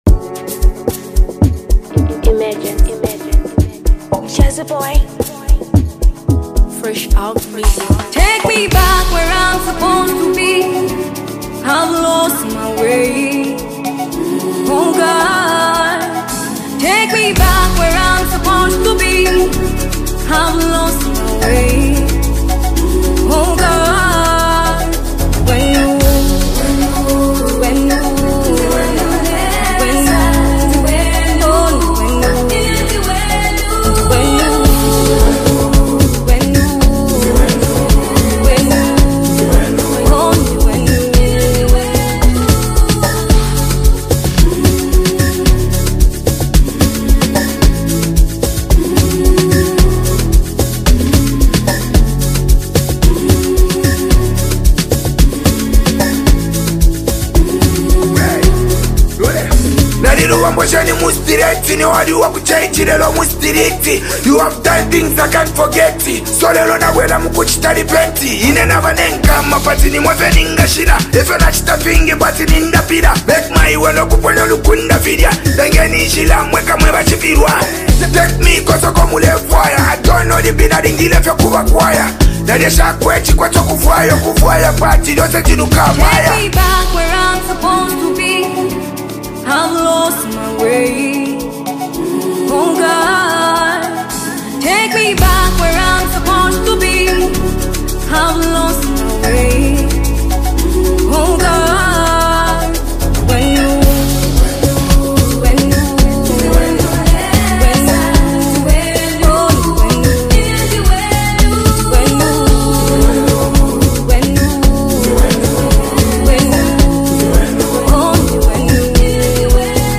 Zambian duo
nostalgic track
signature beats